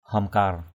/hɔ:m-kar/ (d.) bùa càn khôn = signe magique. haomkar nayah inâ _h>kR nyH in% bùa âm = signe magique (femelle). haomkar nayah amâ _h>kR nyH am% bùa dương = signe...